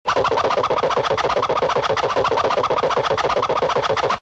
CARTOON - TWIRL 01
Category: Sound FX   Right: Both Personal and Commercial